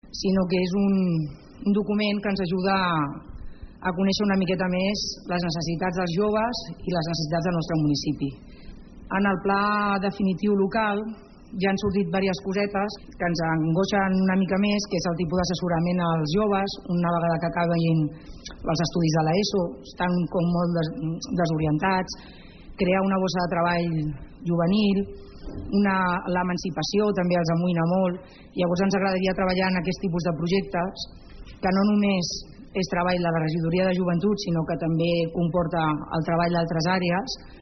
Trobar feina, l’emancipació i la desorientació escolar són les principals preocupacions que detecta l’apartat de diagnosi del document. Són declaracions de Rosa Garcia, regidora de Joventut.